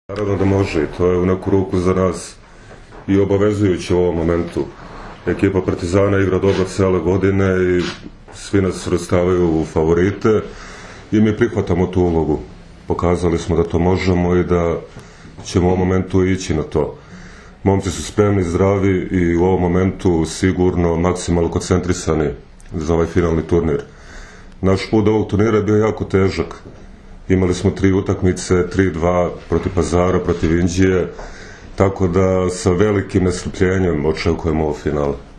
U prostorijama Odbojkaškog saveza Srbije danas je održana konferencija za novinare povodom Finalnog turnira jubilarnog, 50. Kupa Srbije.
IZJAVA